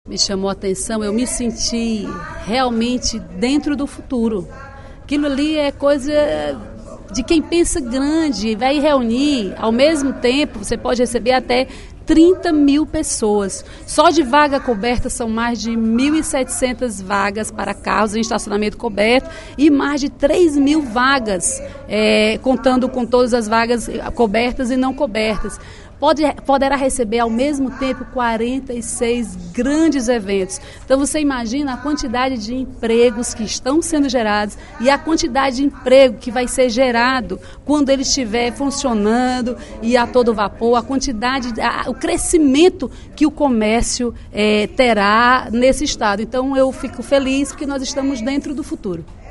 Ela comentou sobre a obra durante seu pronunciamento na sessão plenária desta quinta-feira (08/03).